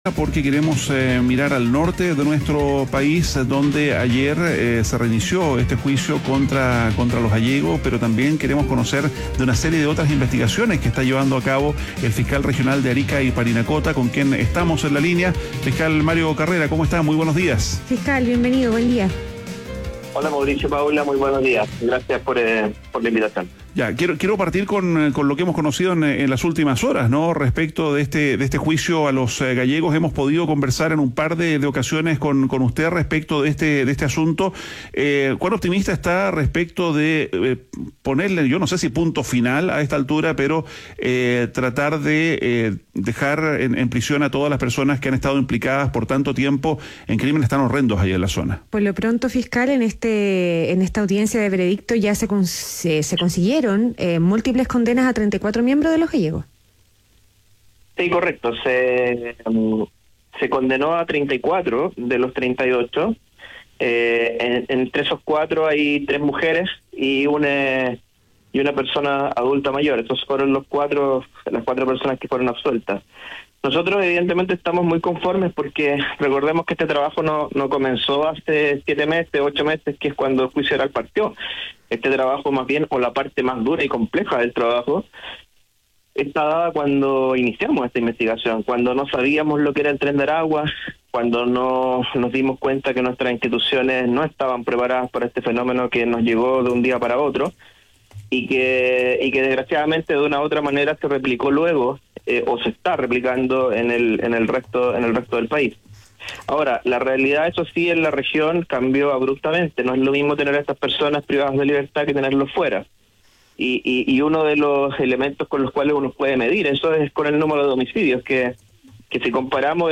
En conversación con ADN Hoy, el fiscal regional de Arica y Parinacota, Mario Carrera, analizó el avance de las investigaciones contra la banda criminal venezolana, destacando que 34 de sus integrantes fueron condenados recientemente.
ADN Hoy - Entrevista a Mario Carrera, fiscal regional de Arica y Parinacota quedó en M2